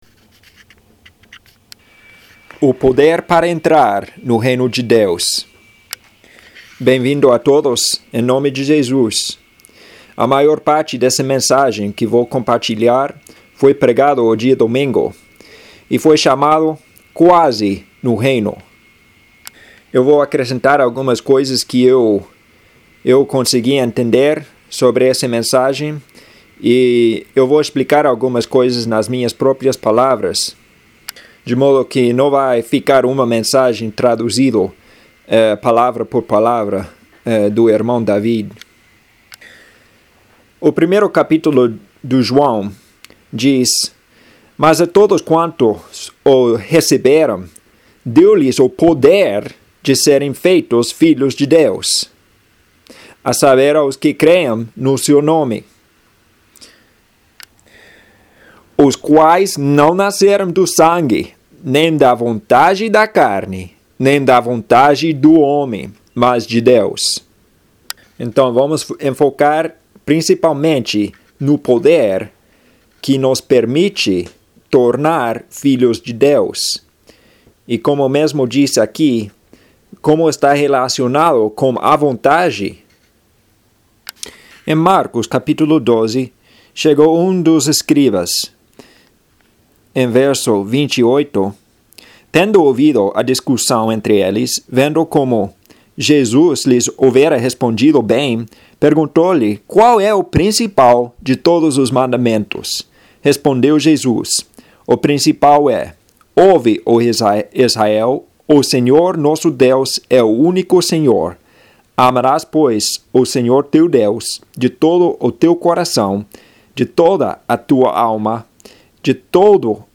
Serm�o